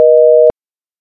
phone-outgoing-busy.oga